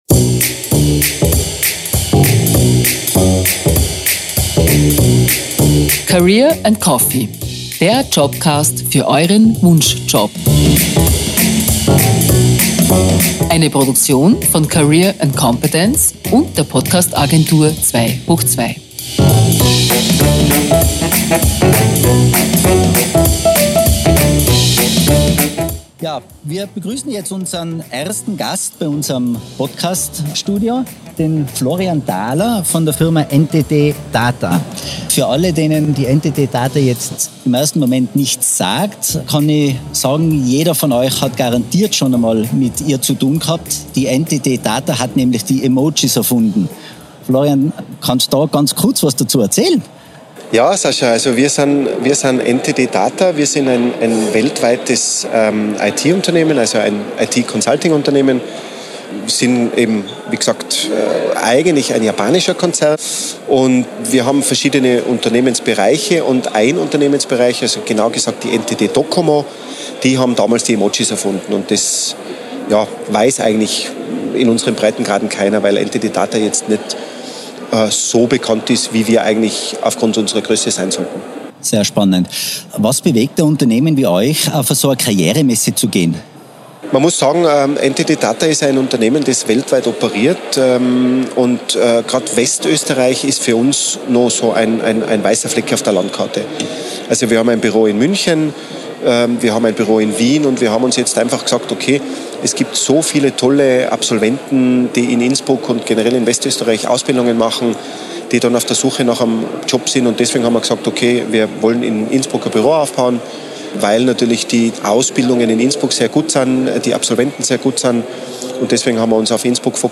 Livemitschnitt von der Career & Competence-Messe mit